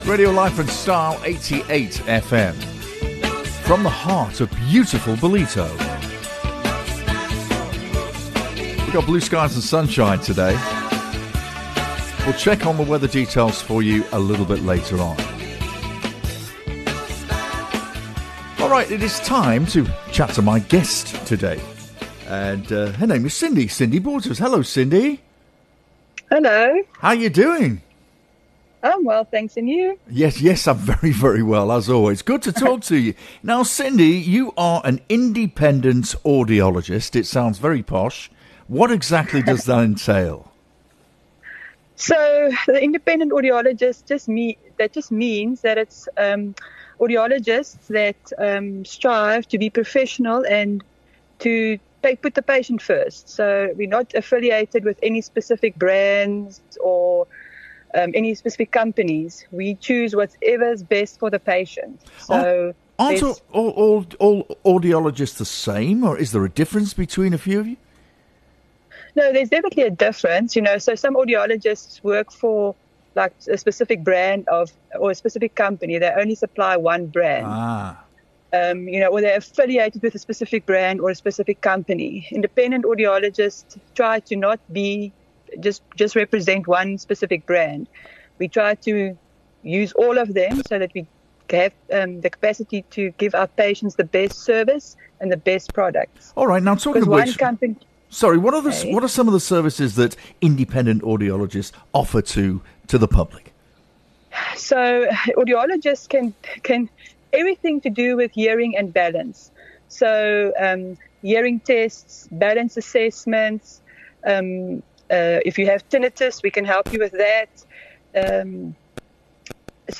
The upcoming interview will highlight the Independent Connected Audiology Network (iCAN), explaining what makes independent audiologists unique, the services they provide beyond hearing aids, and the importance of patient-centred care. Listeners will also gain insights into early signs of hearing loss, links to dementia, tinnitus treatment, and where to find iCAN members across South Africa.